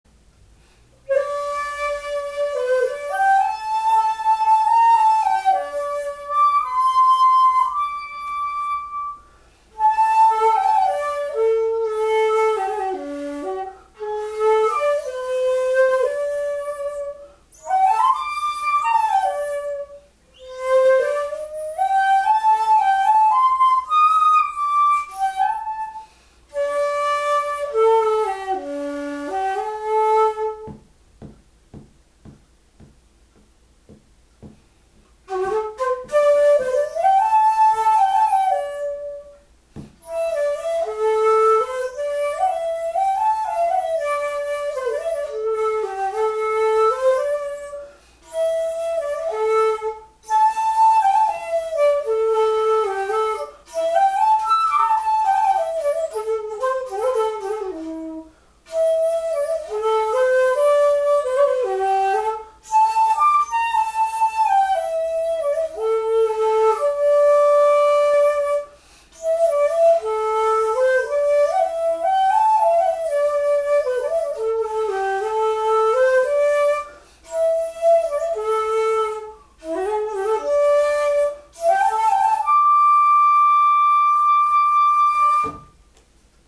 私の技倆はお許し願うとして、こういう華麗な音色で乙ロも素直に響いて鳴ってくれる竹は恐らく尺八愛好家の垂涎の竹でしょう。